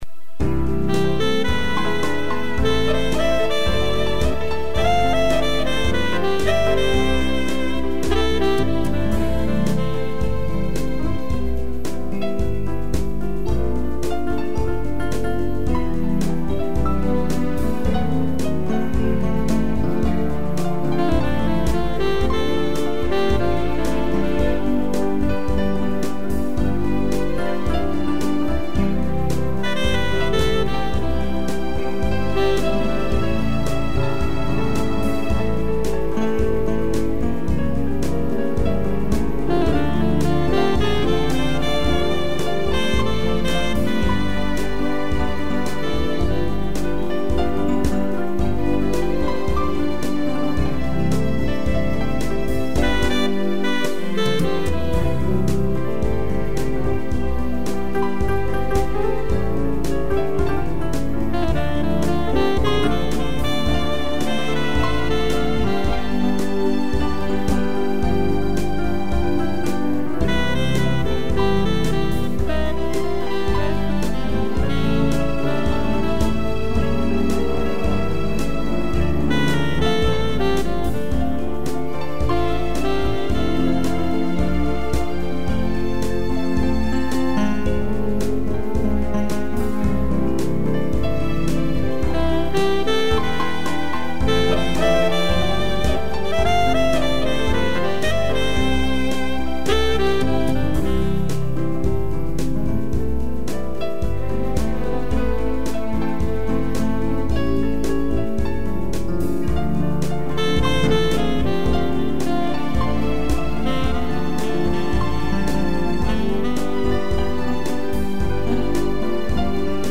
piano, sax e strings
instrumental